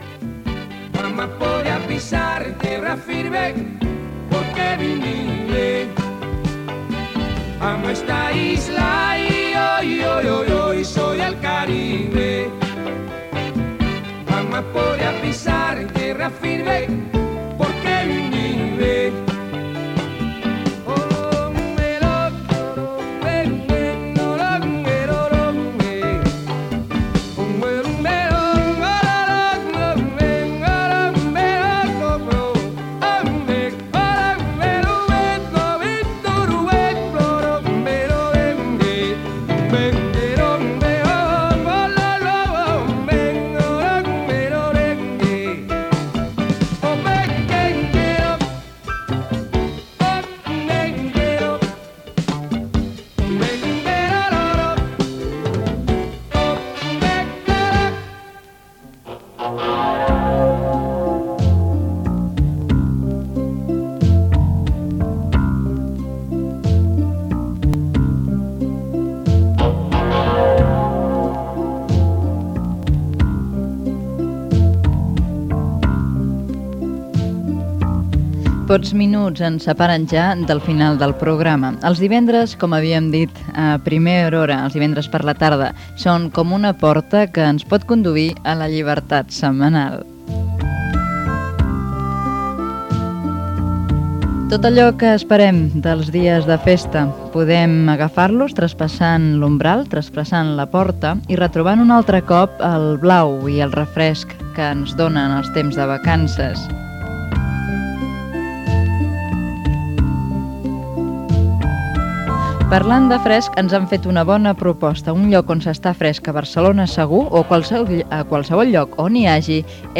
Tema musical, comentari sobre els divendres i resum del que s'ha escoltat al programa, (els cursos de patinatge sobre gel, el Iemen, l'orxata, agenda cultural), equip, comiat
Entreteniment